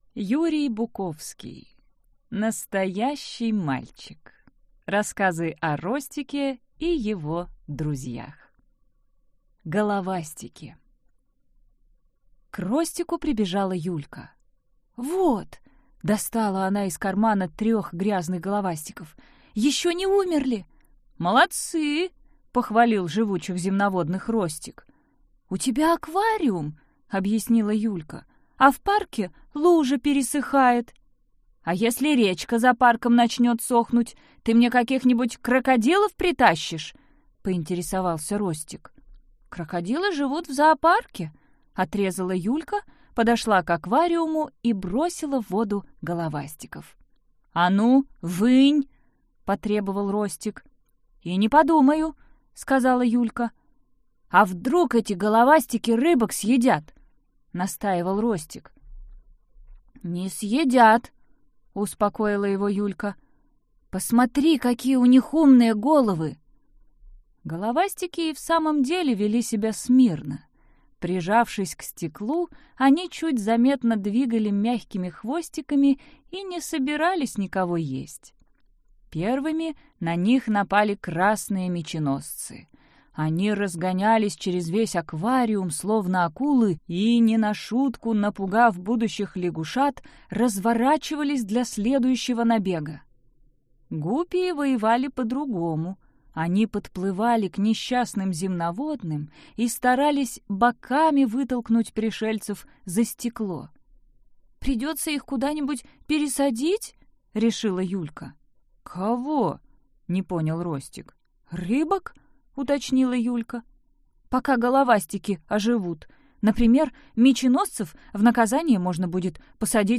Аудиокнига Настоящий мальчик. Рассказы о Ростике и его друзьях | Библиотека аудиокниг